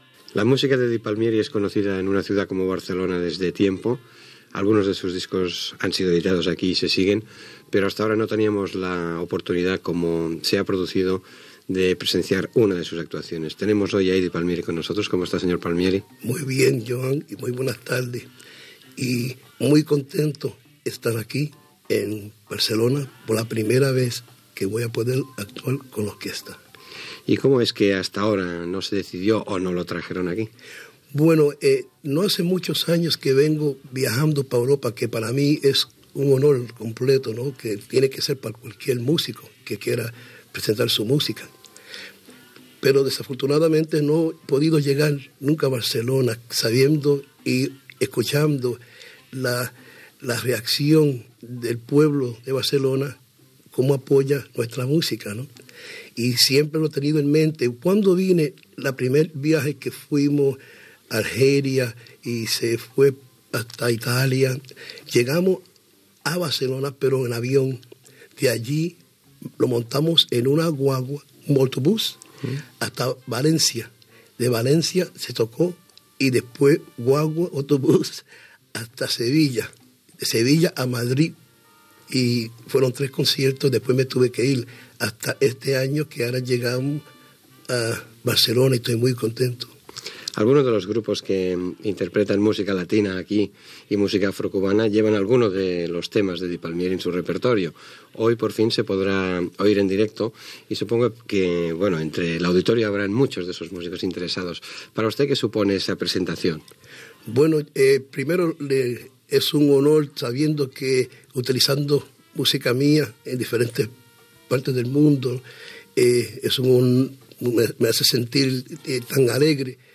Entrevista al pianista Eddie Palmieri que aquell dia actuava a Barcelona per primera vegada
FM
Fragment extret del programa "Memòries de ràdio", emès per Ràdio 4 i accessible al web RTVE Audio